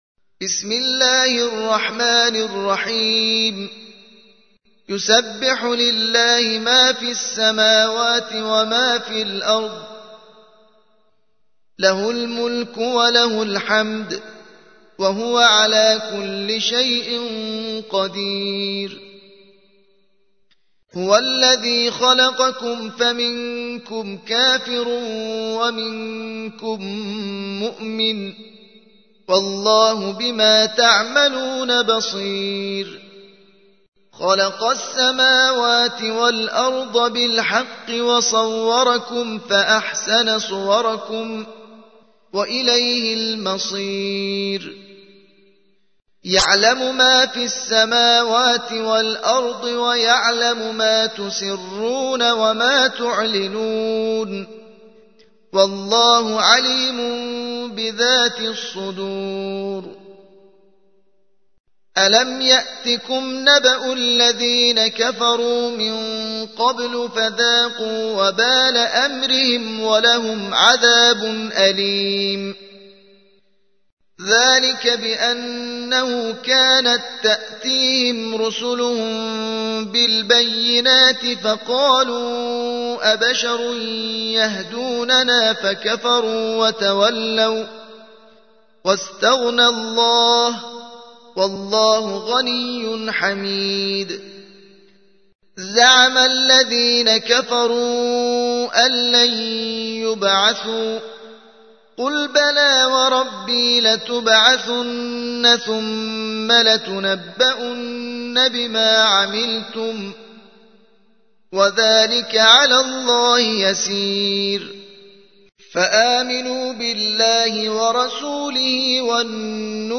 64. سورة التغابن / القارئ